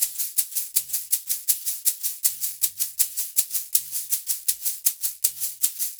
Index of /90_sSampleCDs/USB Soundscan vol.36 - Percussion Loops [AKAI] 1CD/Partition A/05-80SHAKERS
80 SHAK 09.wav